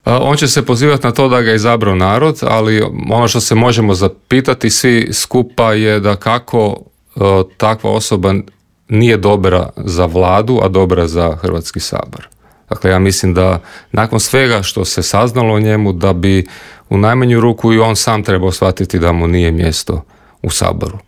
O svemu smo u Intervjuu Media servisa razgovarali sa saborskim zastupnikom SDP-a Mihaelom Zmajlovićem.